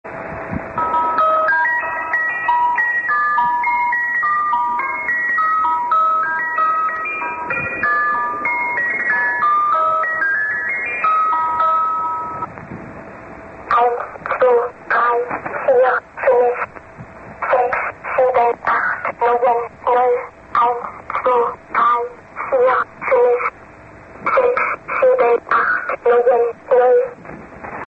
The Swedish Rhapsody: This station operates on a rigid and complicated schedule, in both voice (AM and SSB) and Morse modes (M5).
a slightly less than 30 second or so sample that loops well.